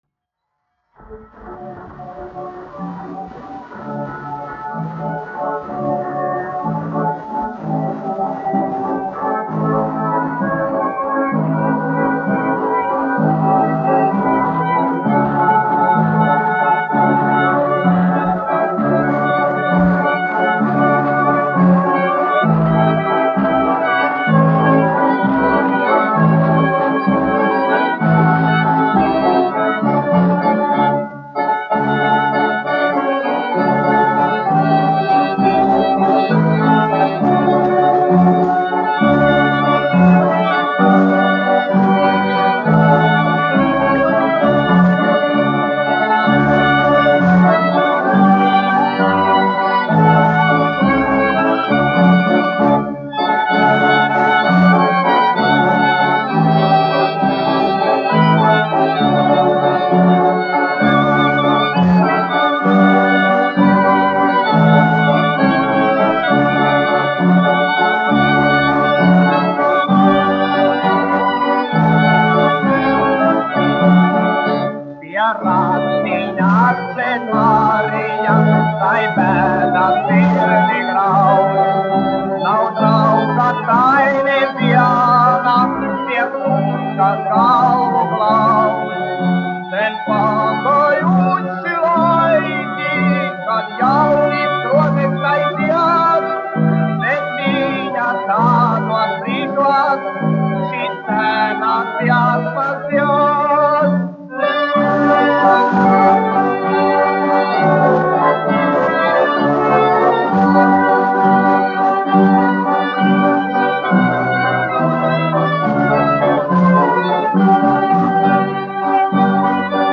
1 skpl. : analogs, 78 apgr/min, mono ; 25 cm
Populārā mūzika -- Latvija
Valši
Skaņuplate